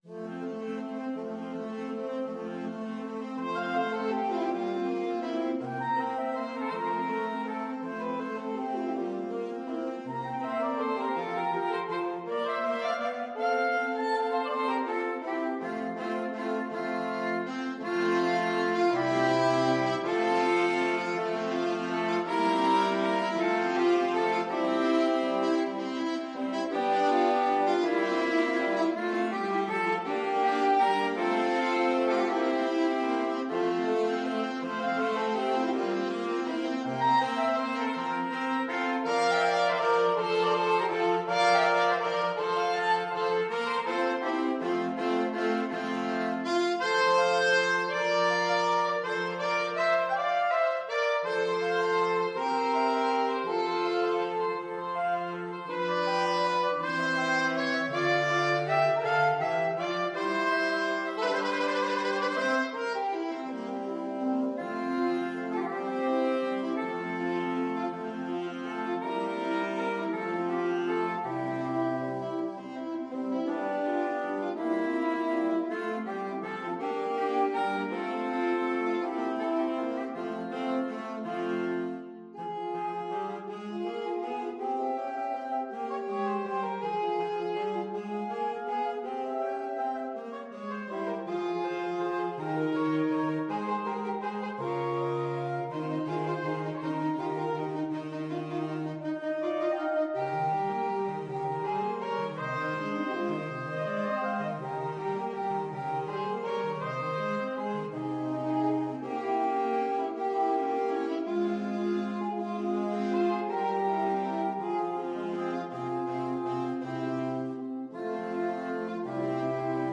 . = 54 Molto lento
6/8 (View more 6/8 Music)
Pop (View more Pop Saxophone Quartet Music)